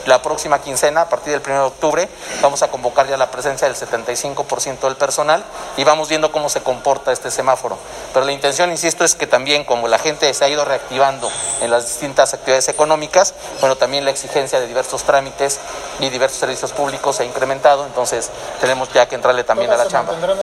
En entrevista, el funcionario municipal comentó que derivado de que en las últimas semanas las y los ciudadanos han recobrado sus actividades económicas, así como existe mayor afluencia en las calles , y ciudadanos efectuando sus trámites, es que se decidió llamar a trabajadores a incorporarse a sus labores en sus oficinas.